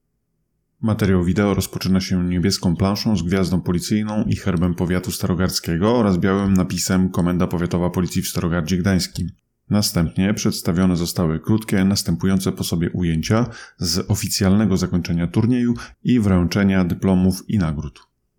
Nagranie audio 2025_04_24_-_Audiodeskrypcja_do_materialu_wideo.mp3